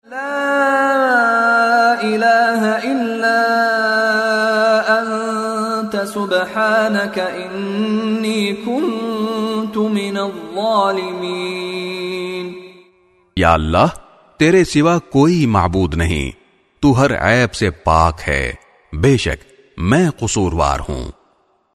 by Mishary Rashid Alafasy
CategoryTilawat
His melodious voice and impeccable tajweed are perfect for any student of Quran looking to learn the correct recitation of the holy book.